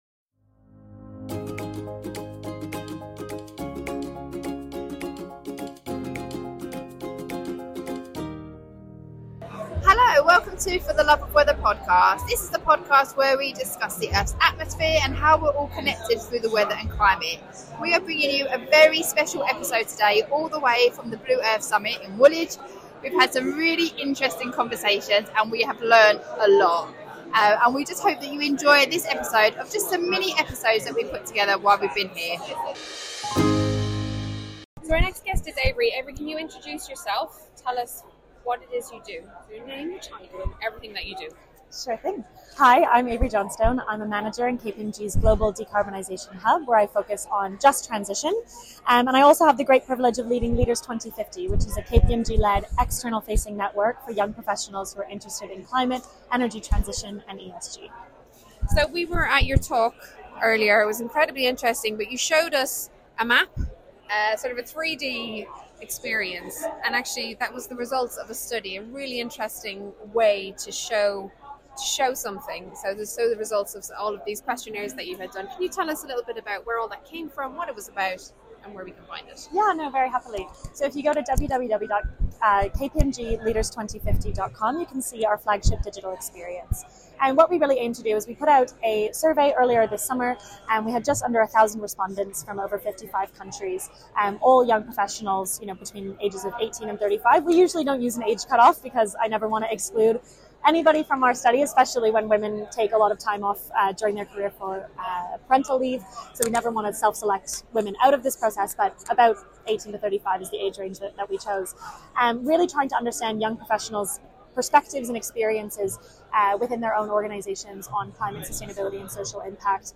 This is the second episode from the Blue Earth Summit 2024.
We captured and shared as many speakers and guests as we could and yet again we were blown away by the amazing conversations we had and most importantly people’s willingness to give us their time to talk about what they were passionate about.